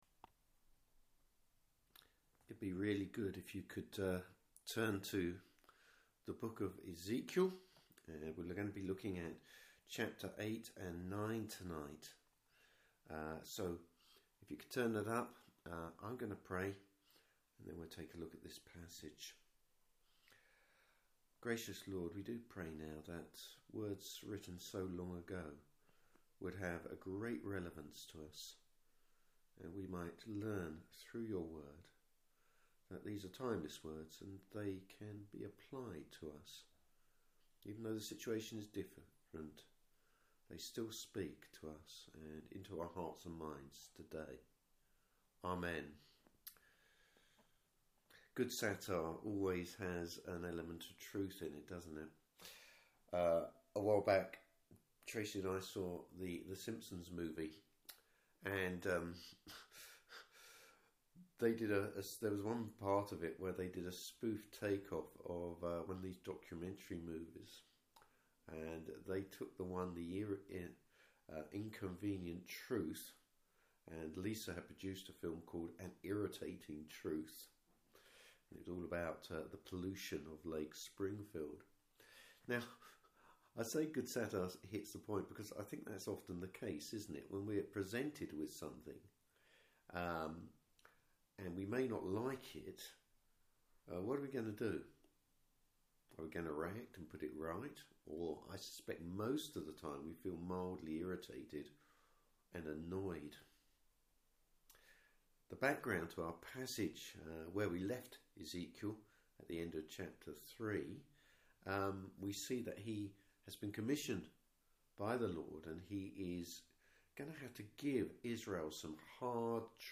Service Type: Evening Service Bible Text: Ezekiel 8-10.